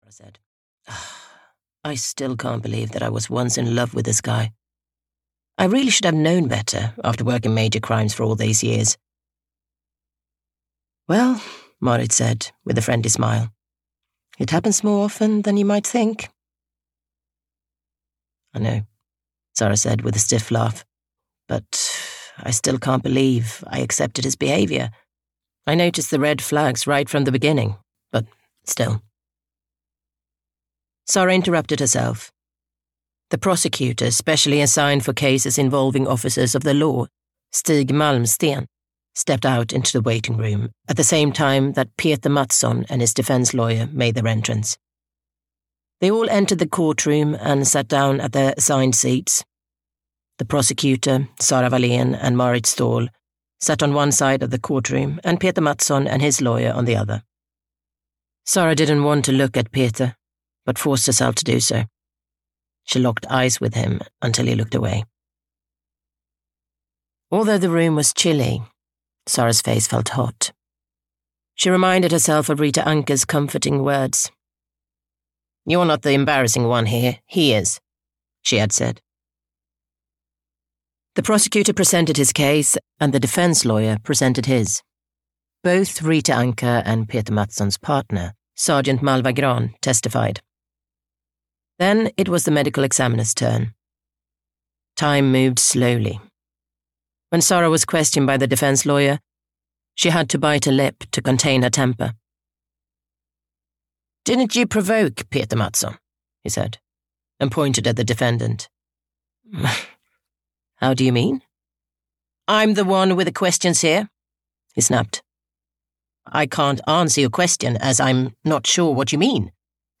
Ukázka z knihy
vigilante-a-sara-vallen-thriller-en-audiokniha